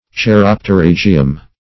Search Result for " cheiropterygium" : The Collaborative International Dictionary of English v.0.48: Cheiropterygium \Chei*rop`te*ryg"i*um\, n.; pl.